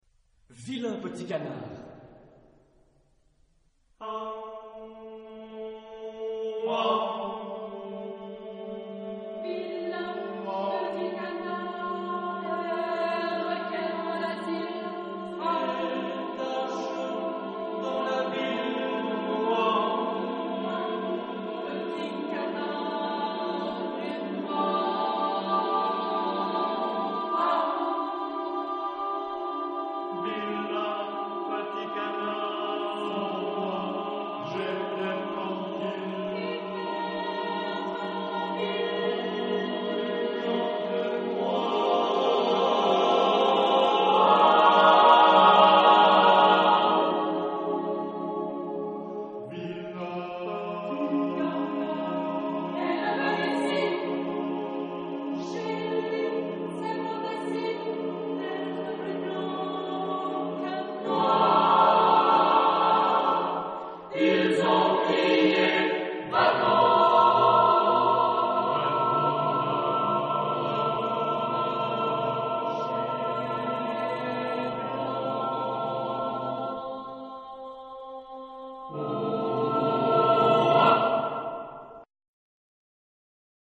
Genre-Style-Form: Choral suite ; Partsong ; Poem ; Secular
Mood of the piece: rhythmic ; slow
Type of Choir: SSAATTBB  (8 mixed voices )